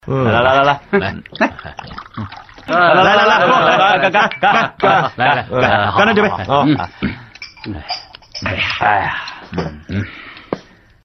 影视剧兄弟喝酒音效免费音频素材下载